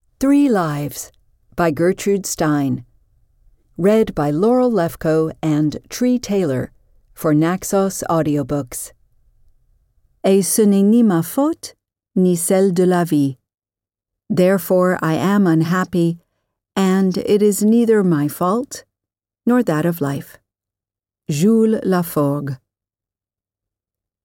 Three Lives (EN) audiokniha
Ukázka z knihy